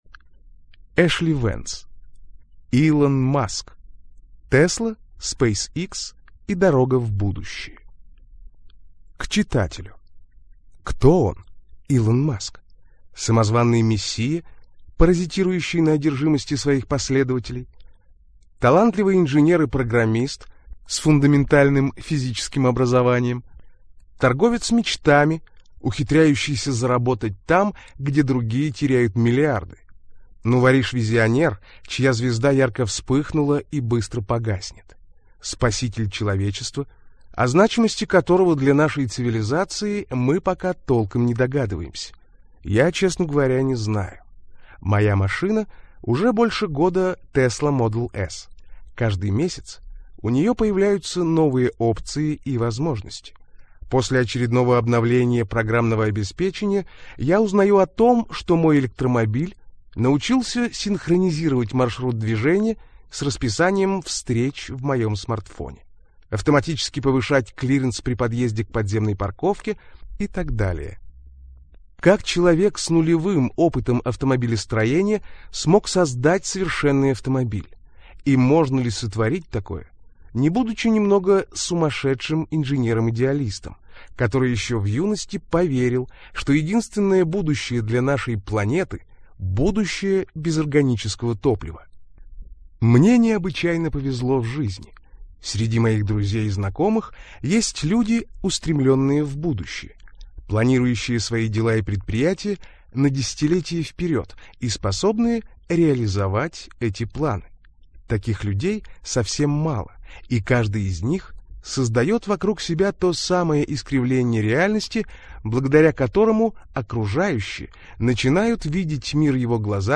Аудиокнига Илон Маск: Tesla, SpaceX и дорога в будущее - купить, скачать и слушать онлайн | КнигоПоиск